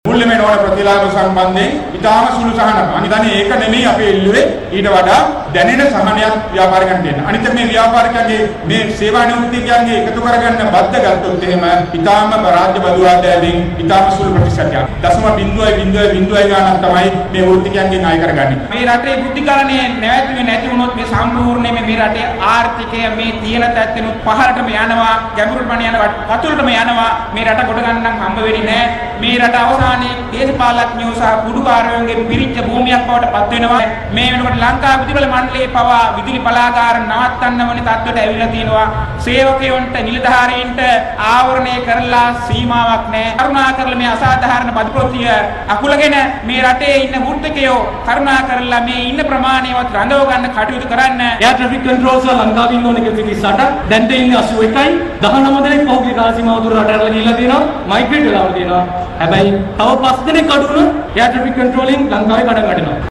එමෙන්ම වෘත්තීය සමිතිවල ප්‍රධාන විරෝධතා රැළිය කොළඹ හයිඩ් පිටියේදී පැවැත්වෙනවා.
මේ එහිදි අදහස් දැක්වූ වෘත්තීය සමිති සාමාජිකයින්.